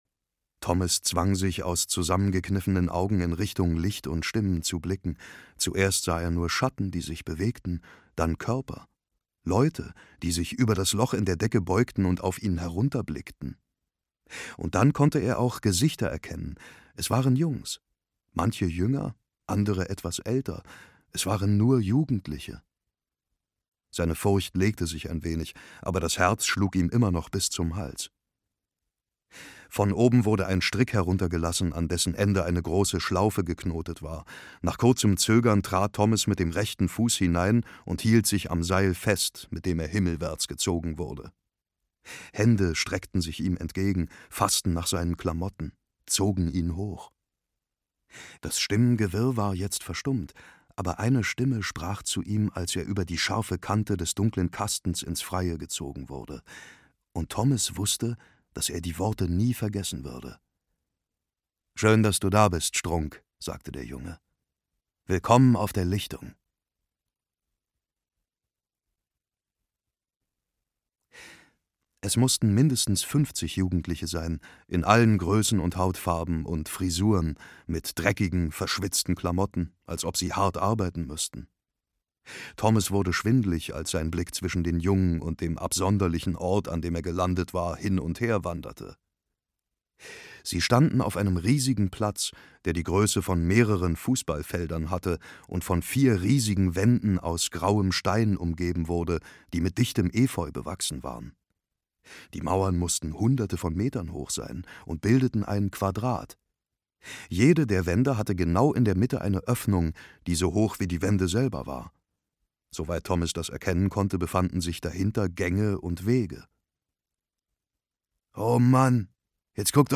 Die Auserwählten - Maze Runner 1: Maze Runner: Die Auserwählten im Labyrinth - James Dashner - Hörbuch - Legimi online